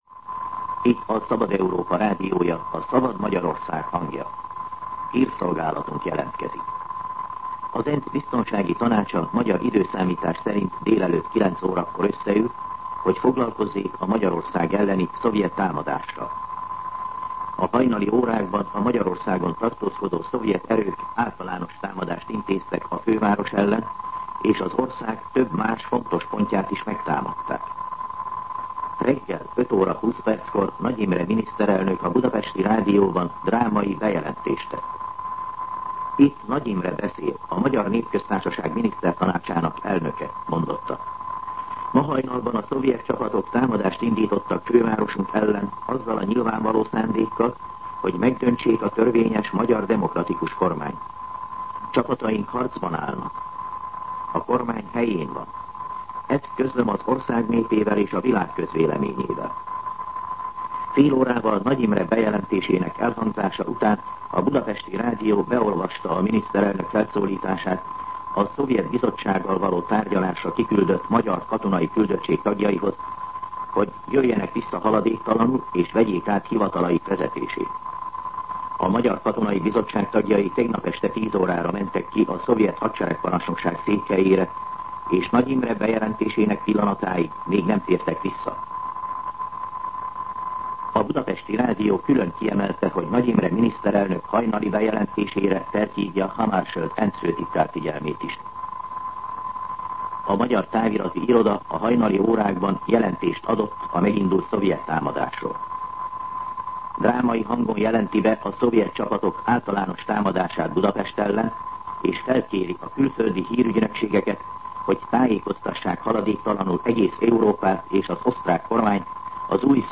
Hírszolgálat